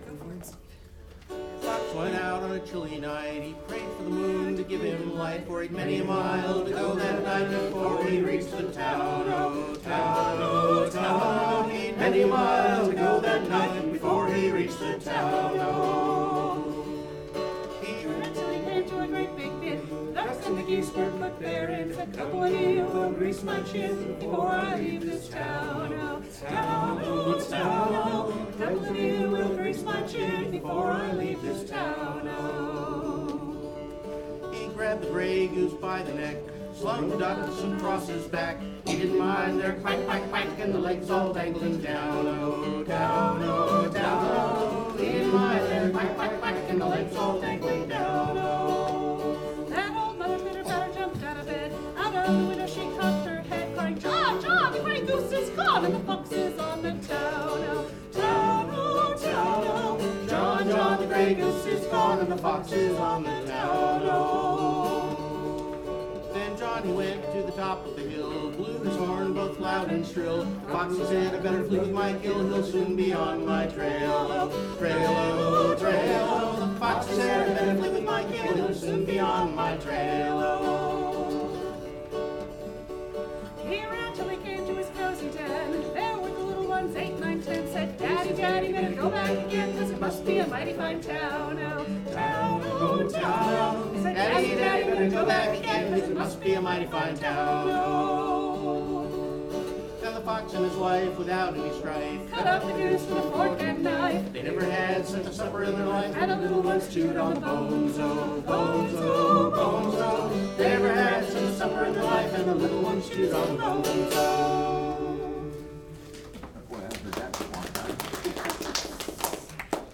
... and our songs at the KinderFilk concert: